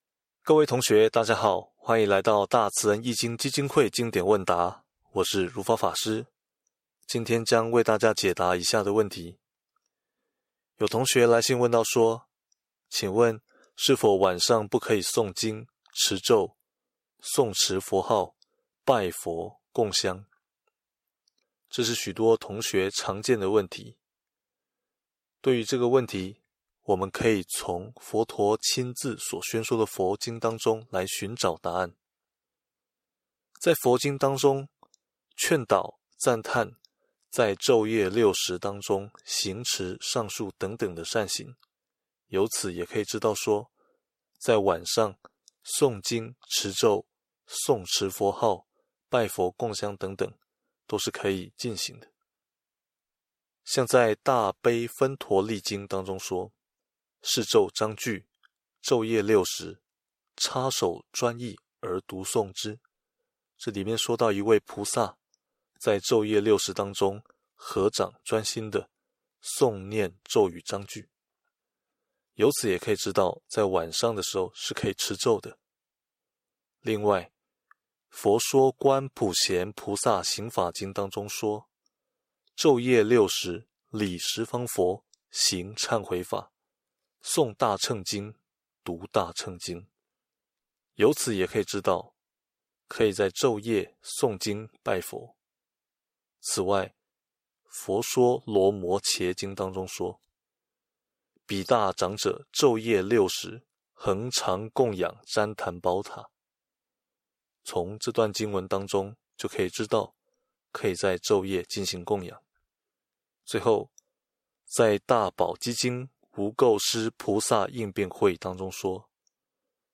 解答法師